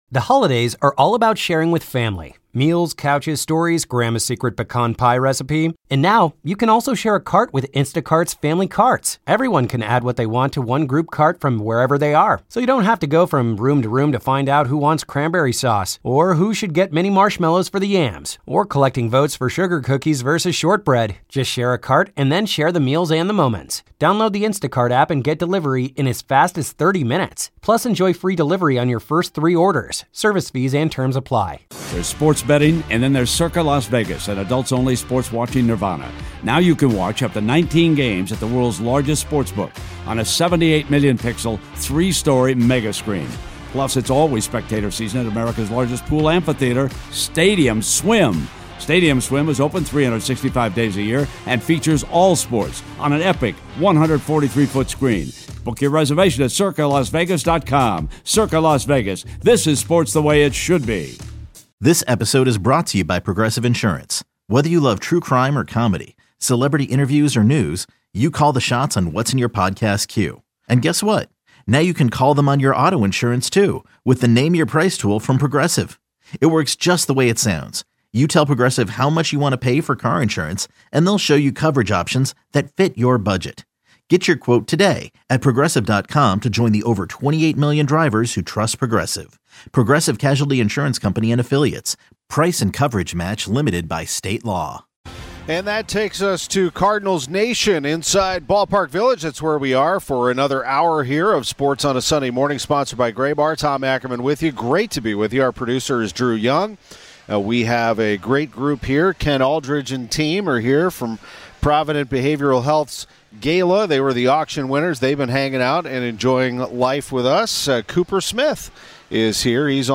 This episode of Sports on a Sunday Morning features updates on the MLB trade deadline, including the St. Louis Cardinals' roster moves, an interview with